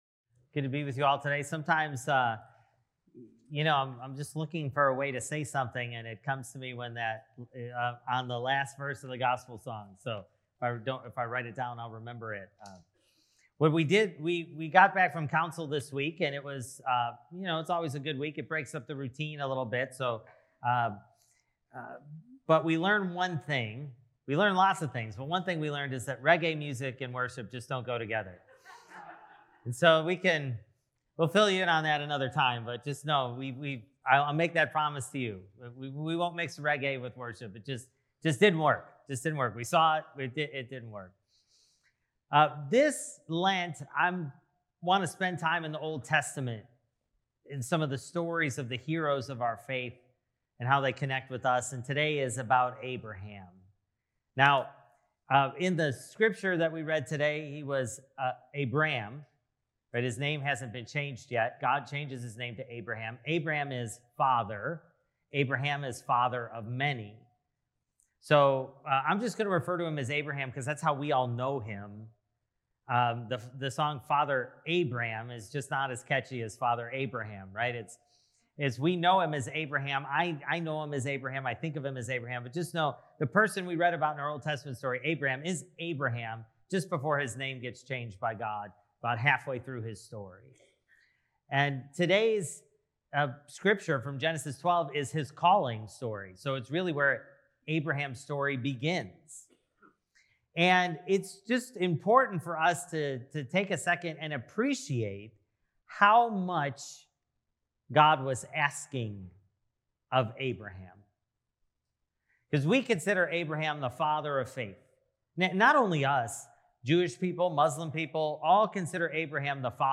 Sermon: Find Your Inner Abraham (Genesis 12:1-4a)